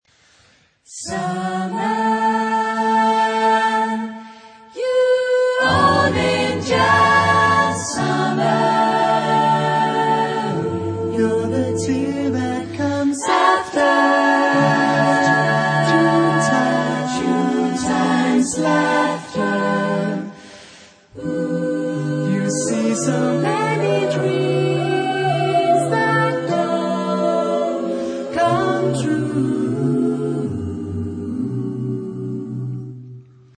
Tipo de formación coral: SSAATTBB  (8 voces Coro mixto )
Tonalidad : la mayor